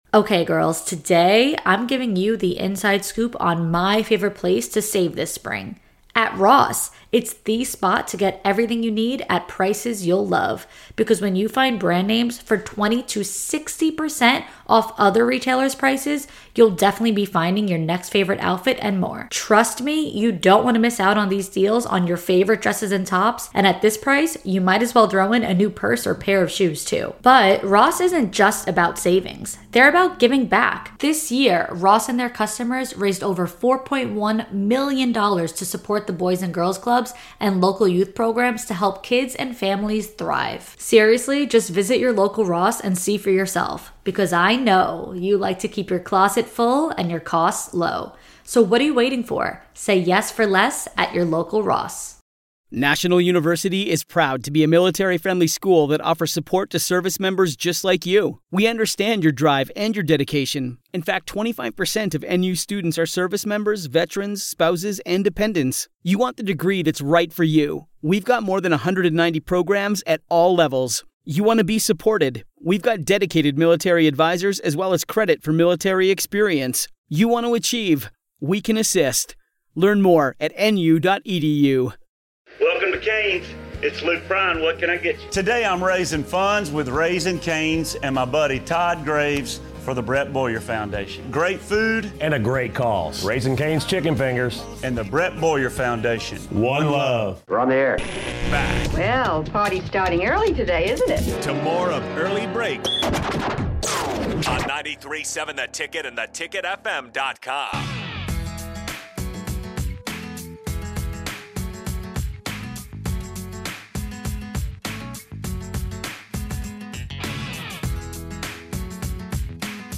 Join these three goofballs from 6-8am every weekday morning for the most upbeat and energetic morning show you'll ever experience.